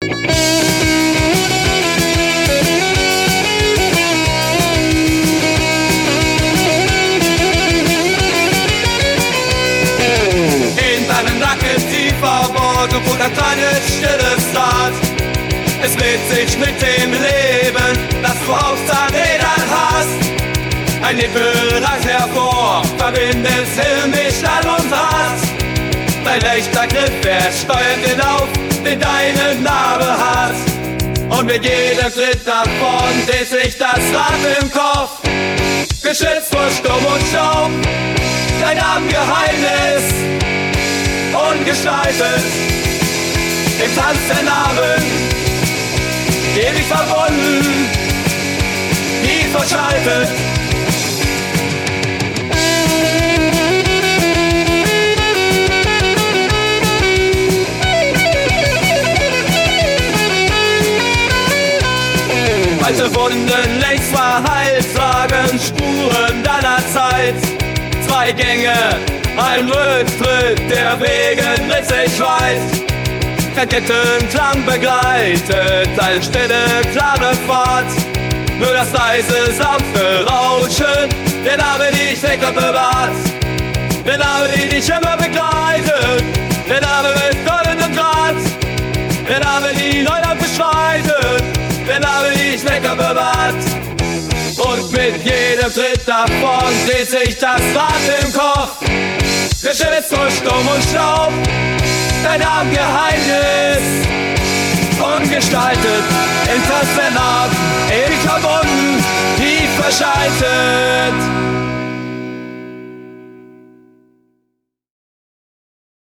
Projekt: KI-unterstütztes Punkalbum